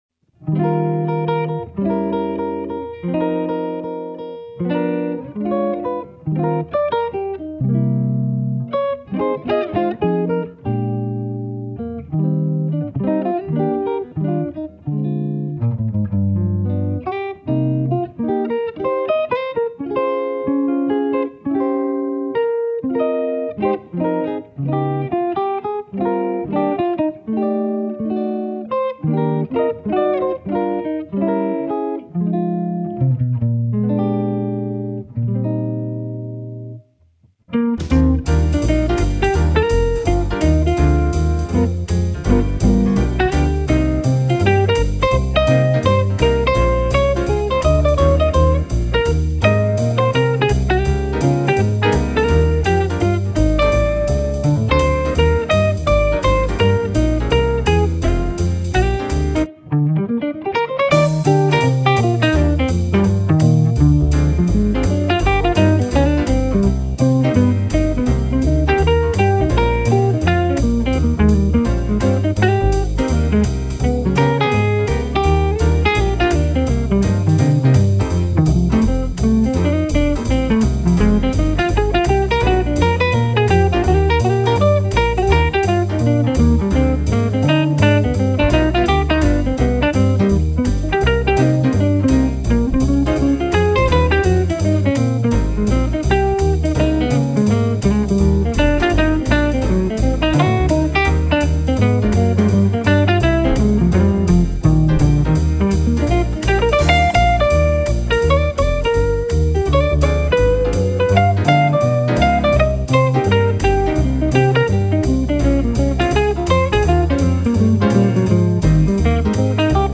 Straight-ahead jazz